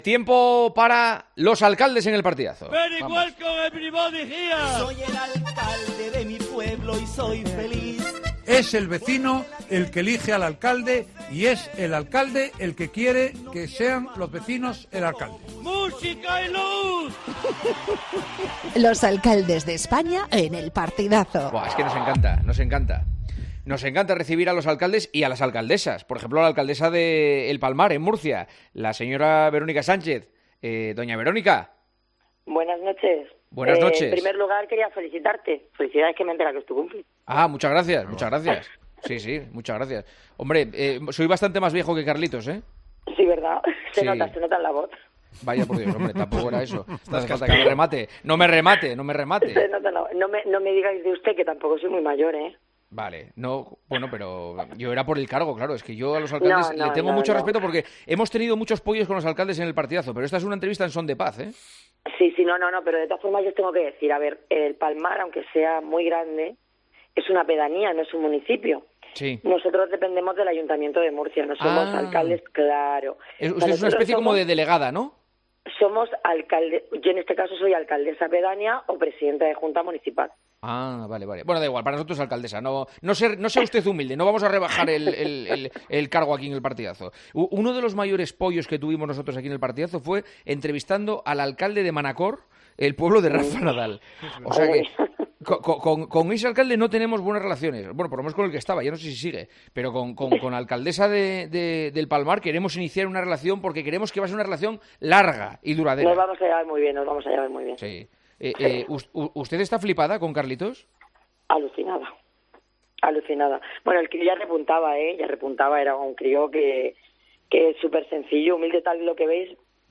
Verónica Sánchez, Alcaldesa de El Palmar, contó en El Partidazo de COPE cómo se vivió la euforia con la que recibieron a Carlos Alcaraz, vigente vencedor del Mutua Madrid Open.